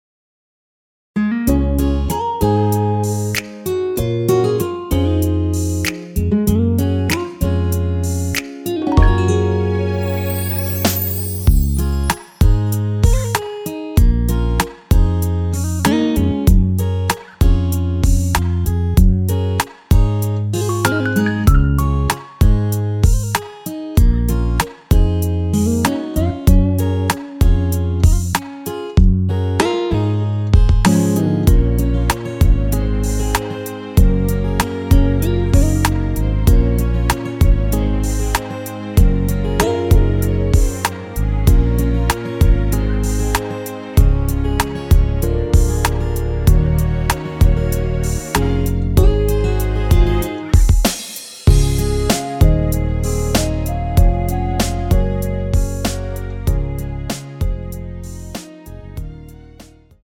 원키에서(-7)내린 MR입니다.
Db
앞부분30초, 뒷부분30초씩 편집해서 올려 드리고 있습니다.
중간에 음이 끈어지고 다시 나오는 이유는